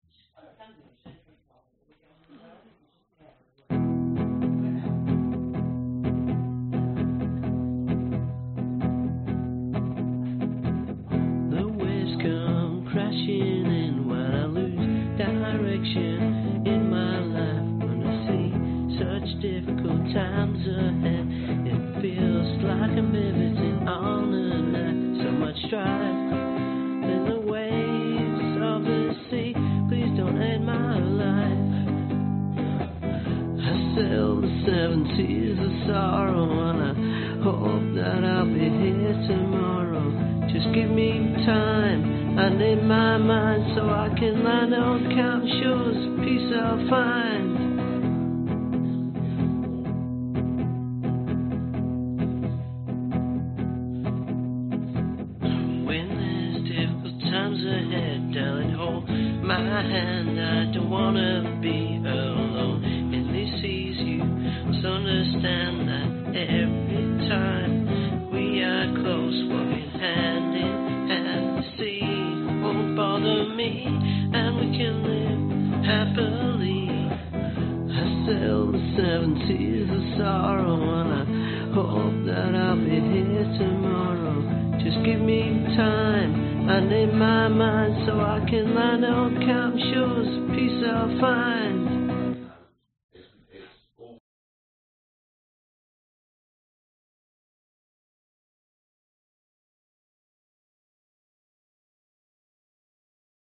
描述：非常粗糙的演示，灵感来自披头士、鲍勃迪伦等。
标签： 原声 男声 吉他
声道立体声